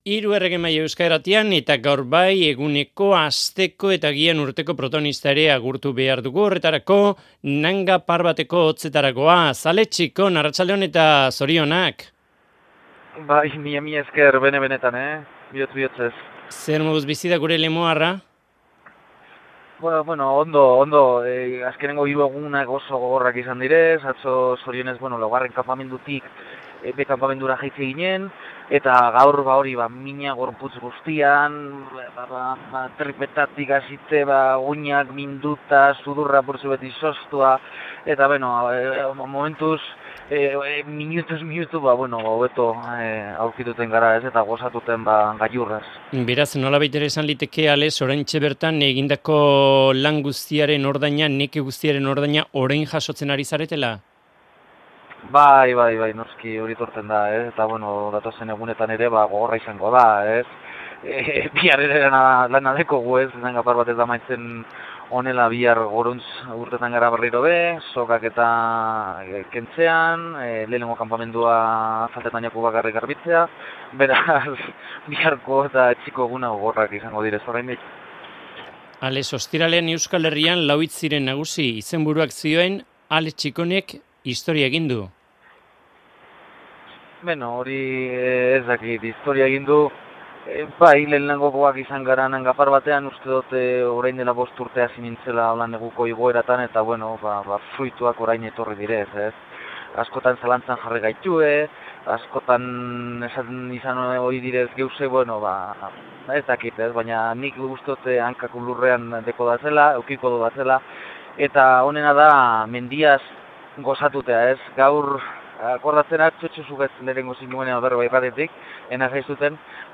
Alex Txikonek Hiru Erregeen Mahaiari eskeini dio Nanga Parbateko gailurra zapaldu ondorengo aurreneko elkarrizketa.